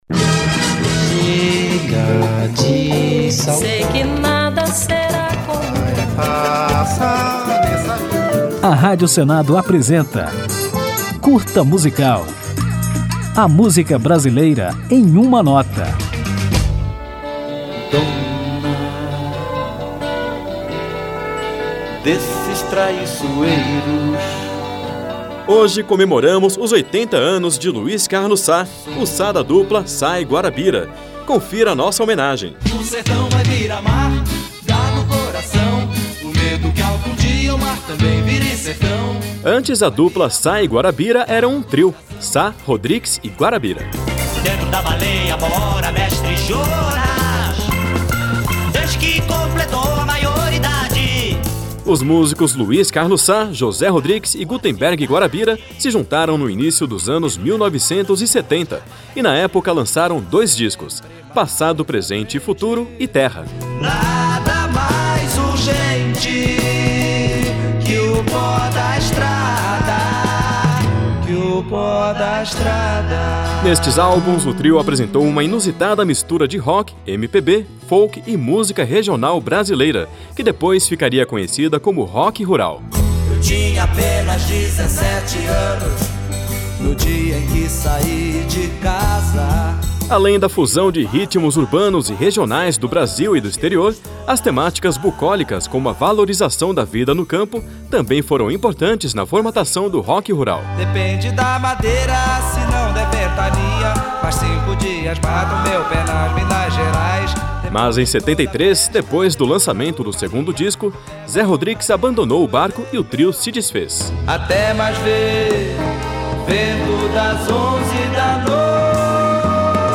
Mas, nesta homenagem ao músico, você vai conhecer a história do trio inventor do rock rural: Sá, Rodrix e Guarabyra, que veio antes da dupla. Ao final, ouviremos a música Primeira Canção da Estrada, sucesso de Sá, Rodrix e Guarabyra, lançado em 1972.